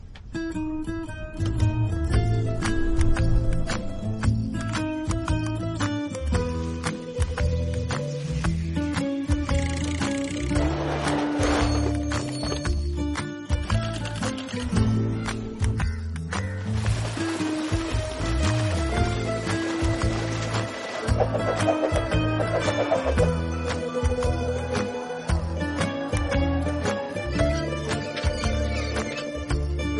rustic music